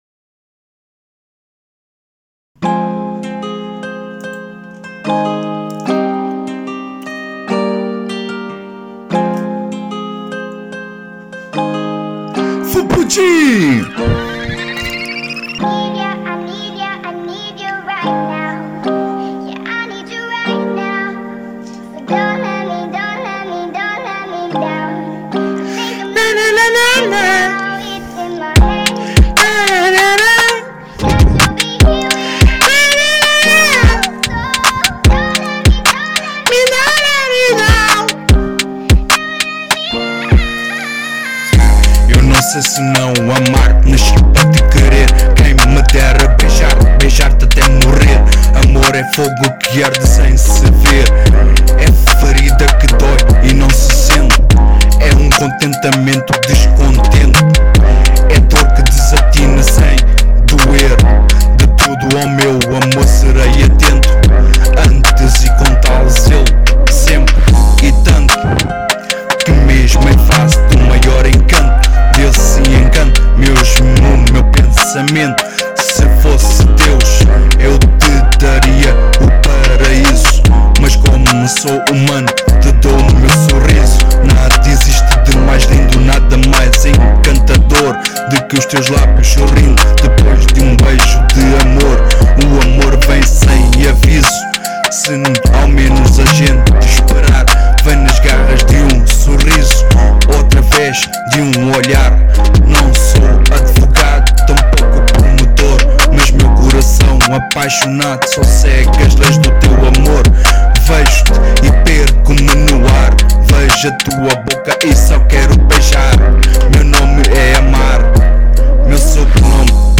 EstiloEletrônica